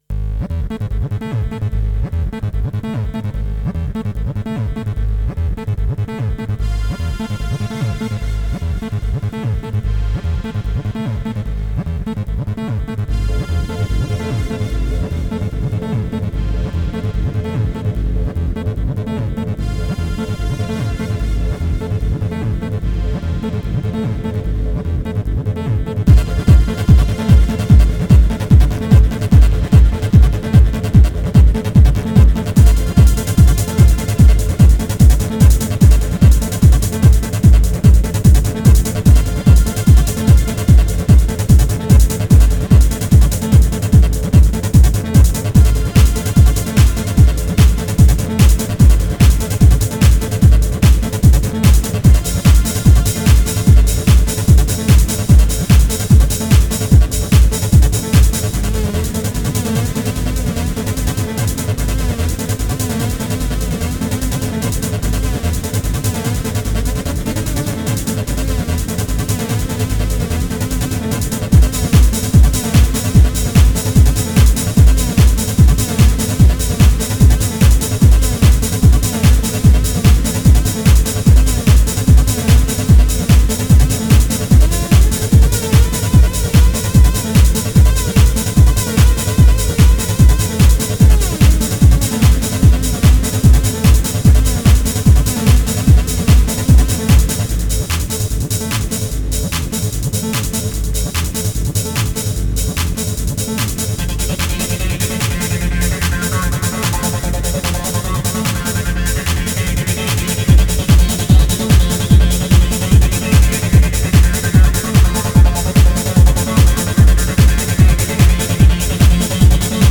Файл в обменнике2 Myзыкa->Psy-trance, Full-on
Style: Psy-Trance, Goa